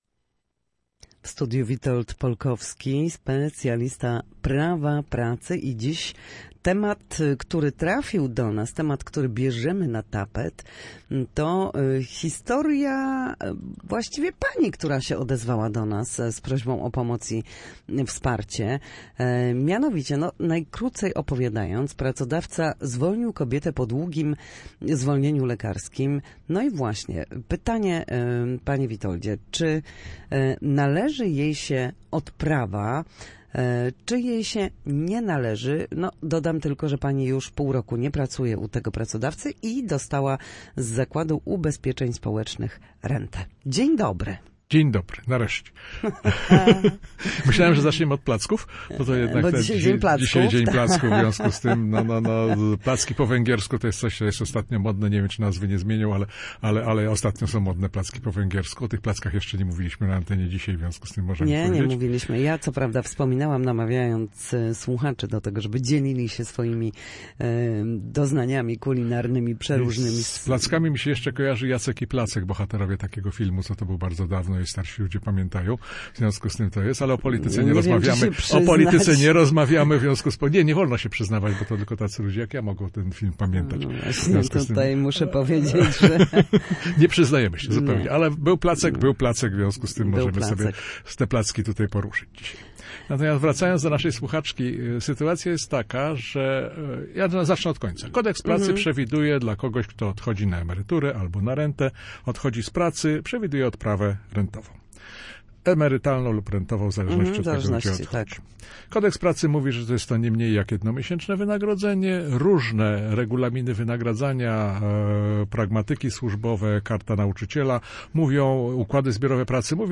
Zwolnienie po chorobie a prawo do odprawy. Ekspert odpowiada na pytanie słuchaczki